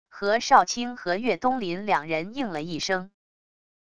何少卿和岳东林两人应了一声wav音频生成系统WAV Audio Player